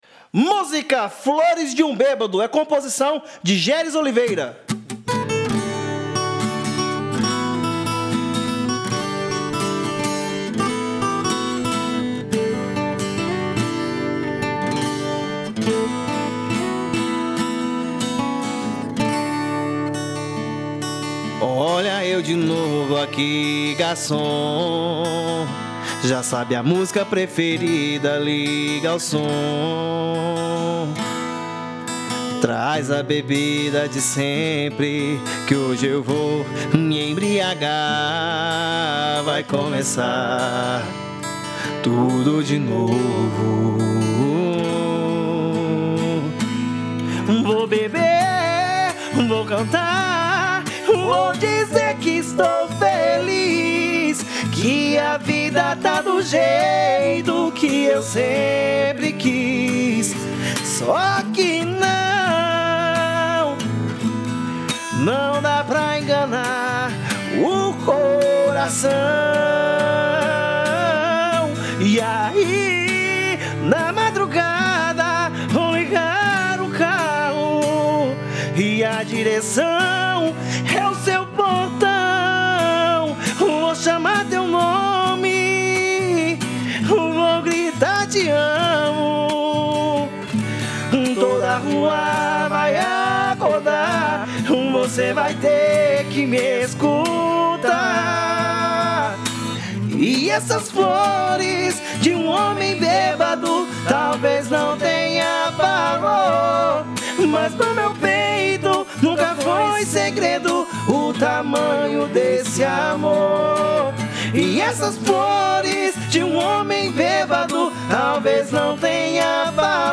EstiloJingles / Spots